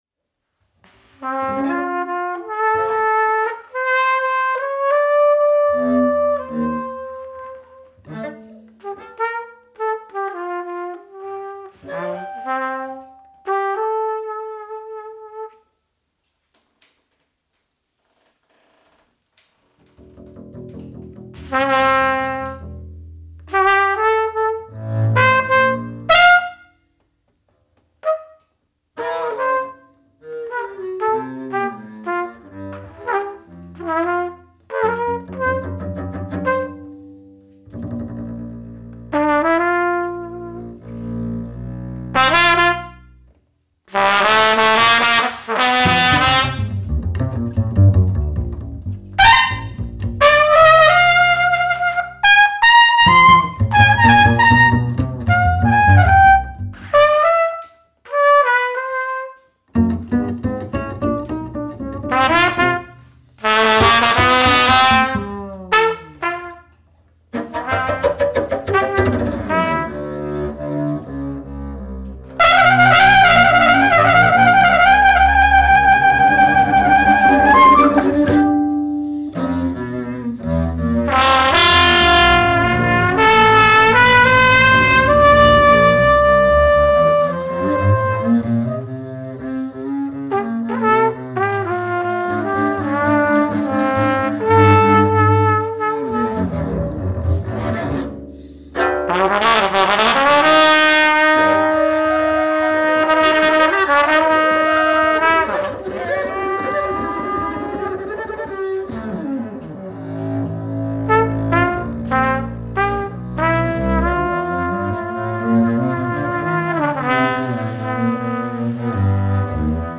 guitar
bass